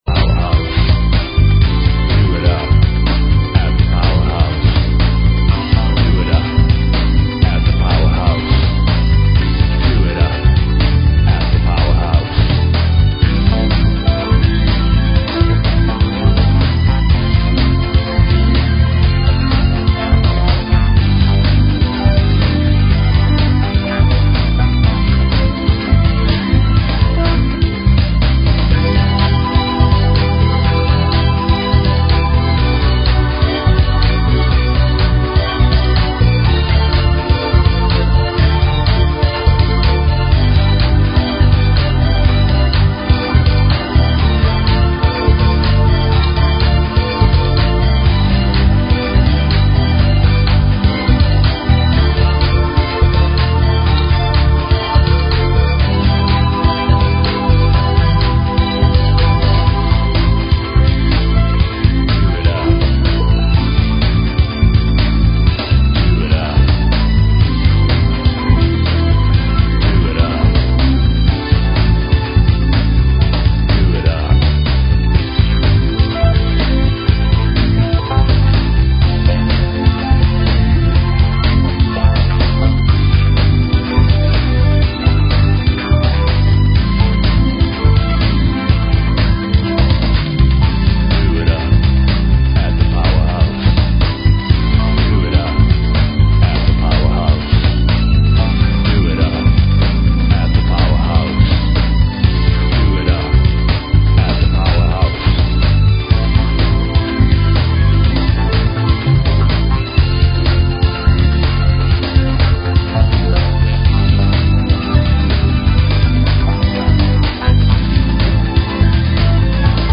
Talk Show Episode, Audio Podcast, Rock_My_Soul_Radio and Courtesy of BBS Radio on , show guests , about , categorized as